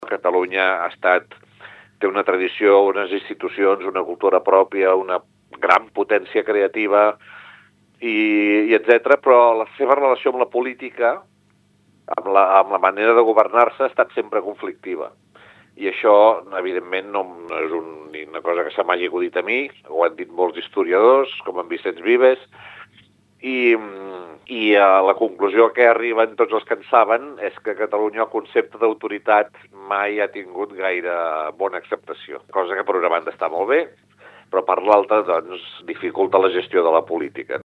Toni Soler, monologuista de ‘Per què Catalunya no és una potència mundial?’
Toni-Soler-02.-Monoleg-conferencia.mp3